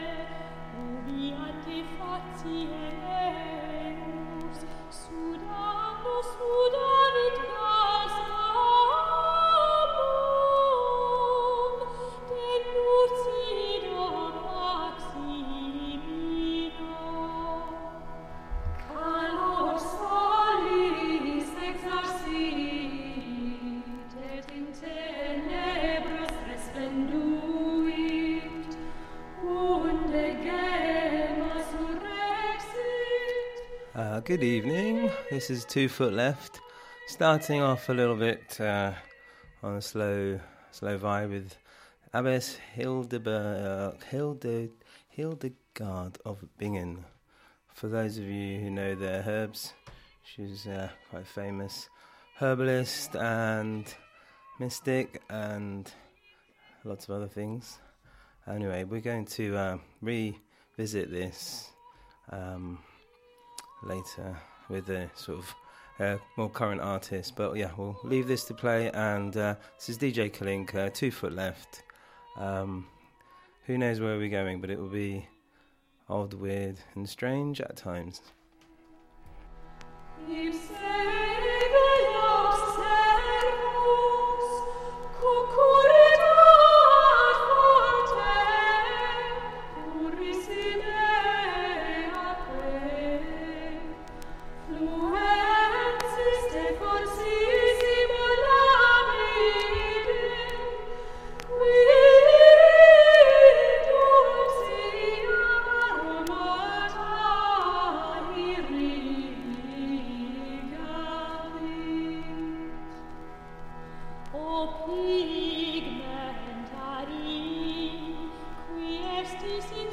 Underground, local, global, universal music and people.